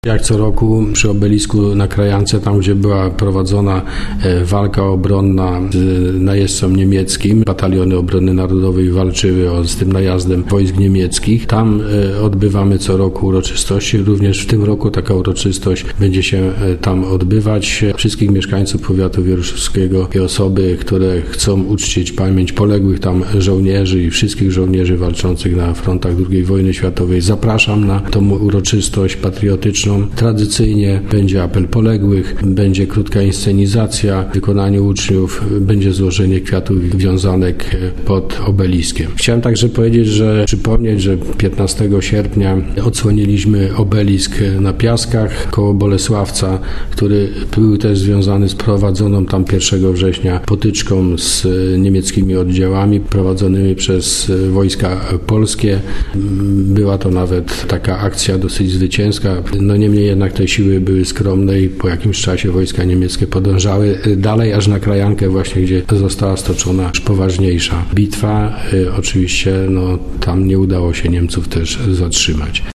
– zapraszał starosta powiatu wieruszowskiego, Andrzej Szymanek.